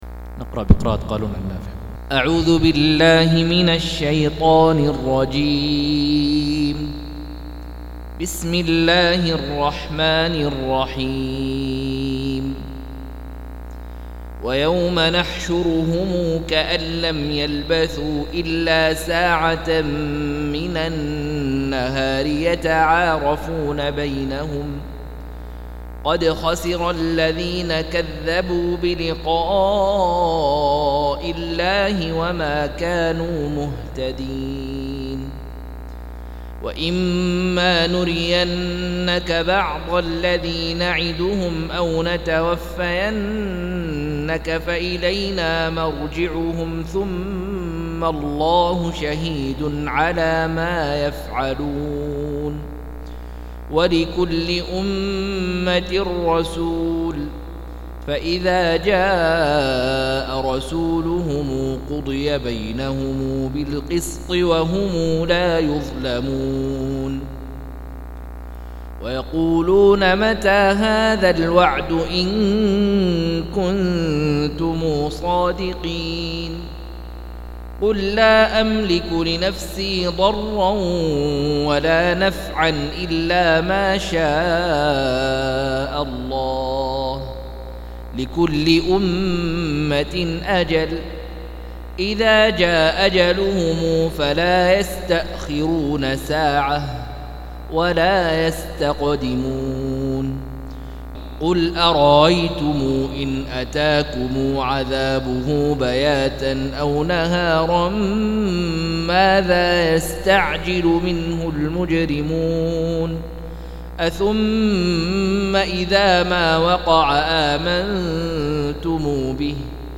204- عمدة التفسير عن الحافظ ابن كثير رحمه الله للعلامة أحمد شاكر رحمه الله – قراءة وتعليق –